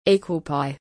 P.S. eqlpy is pronounced
ˈiːkwəl paɪ.